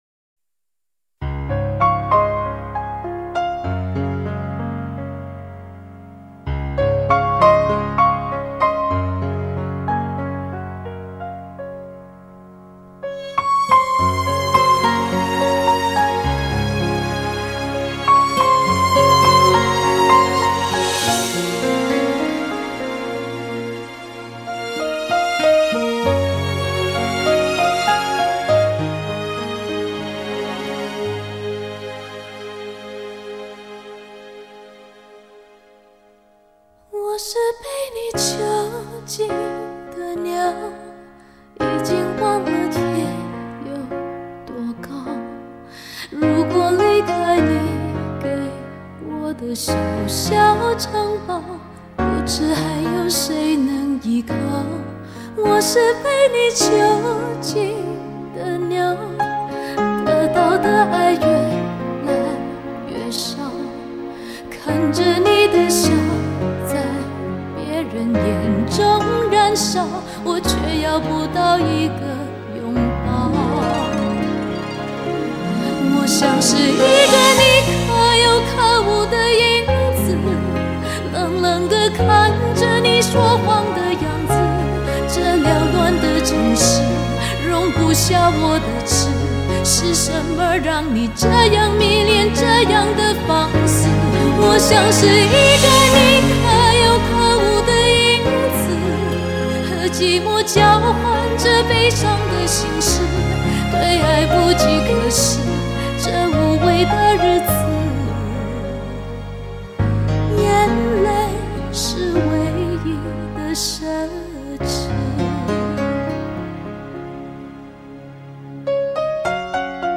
高密度24BIT数码录音